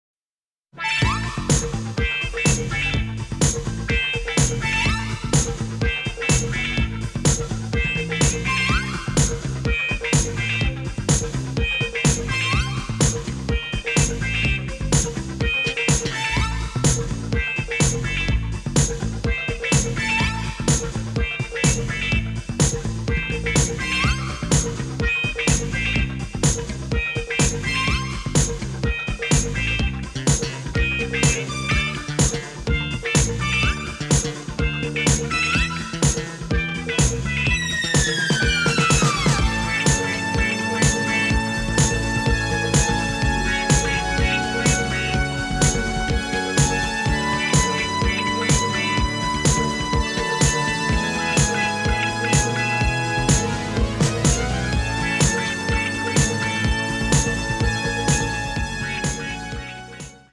in stereo and mint condition